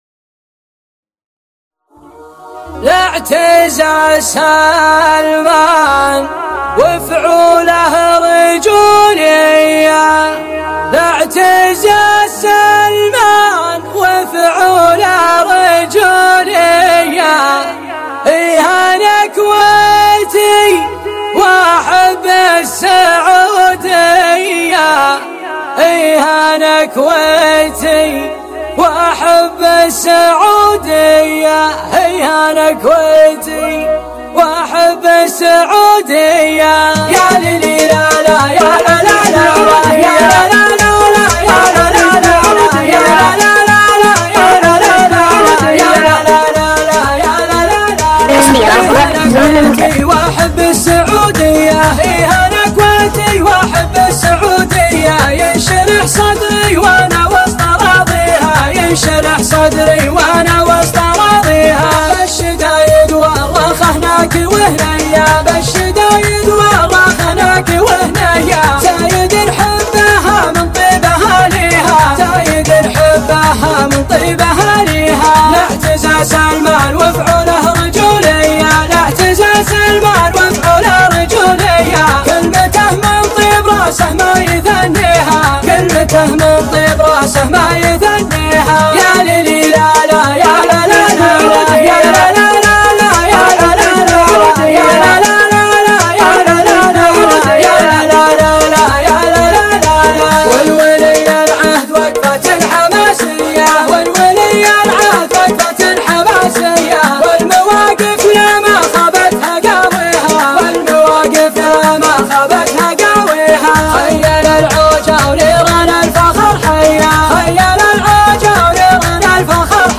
شيلة (وطنية)